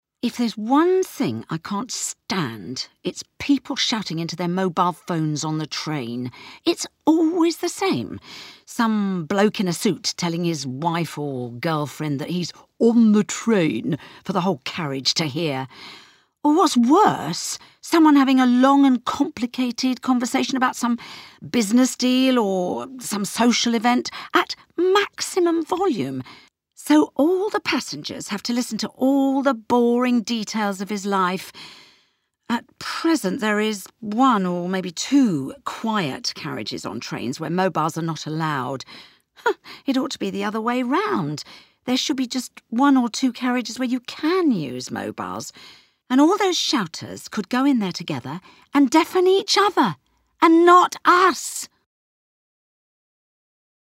This activity provides short listening practice based around a monologue about mobile phones. The monologue relays how the speaker feels about mobile phones in society, particularly on trains.